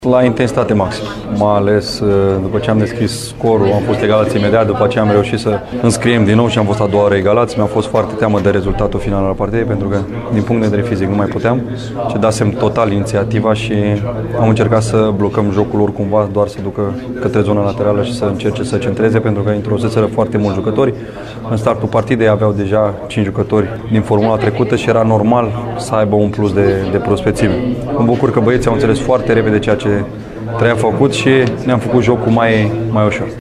Selecționerul Mirel Rădoi a dezvăluit, la rândul său, cum a trăit meciul de pe bancă:
Mirel-Radoi-cum-a-trait-meciul.mp3